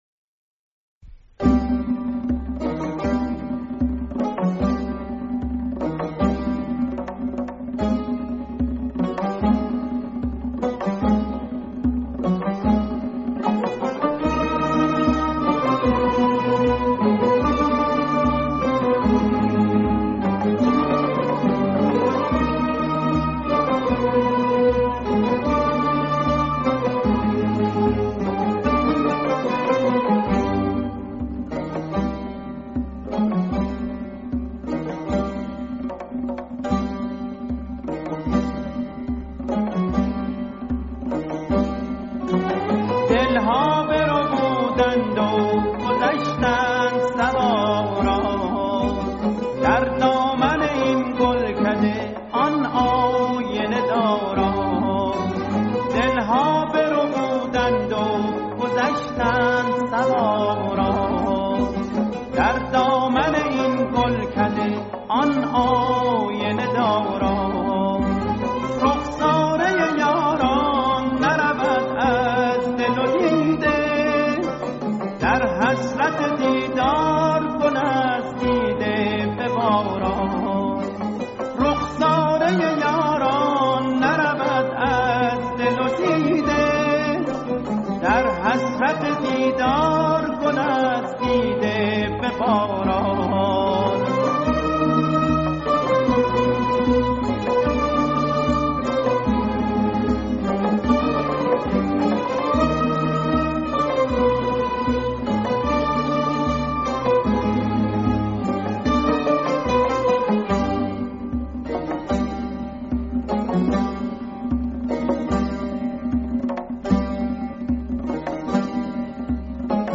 تصنیف در فضای موسیقی دستگاهی